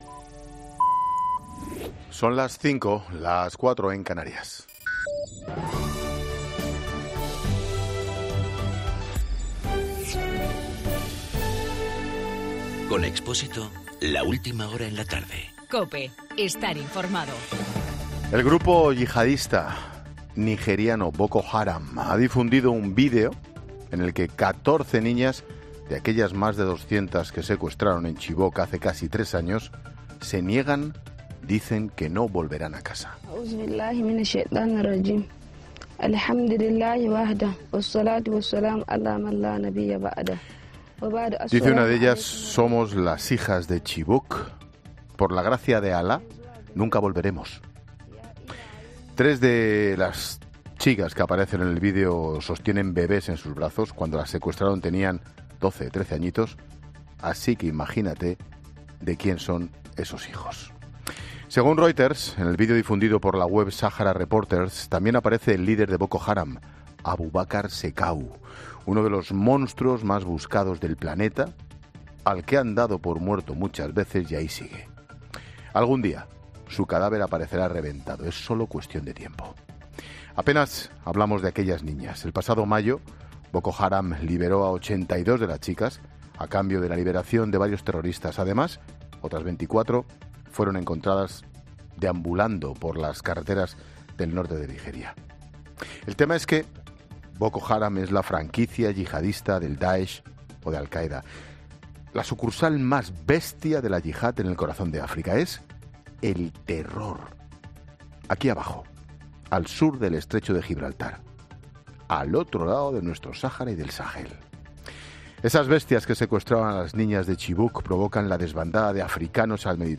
Monólogo de Expósito
El comentario de Ángel Expósito sobre el vídeo difundido por Boko Haram.